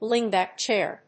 アクセントslíngbàck chàir